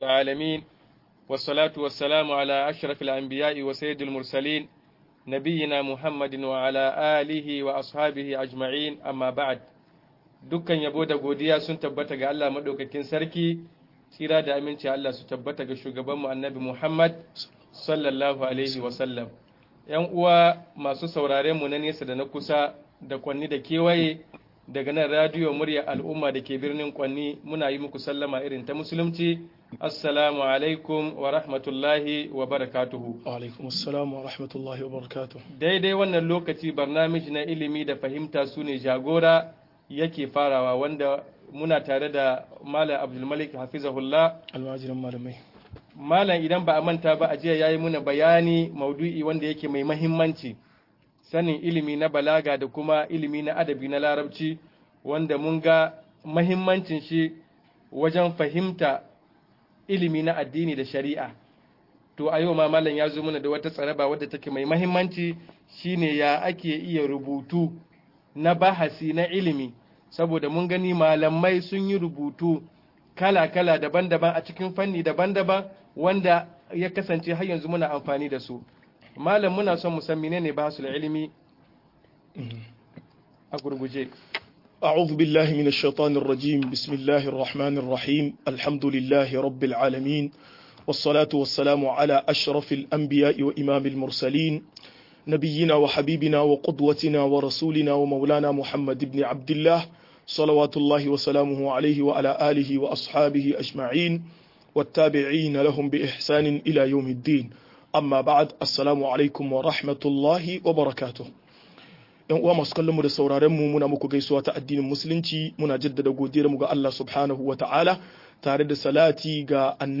Ka'idodin bincike a Muslunci - MUHADARA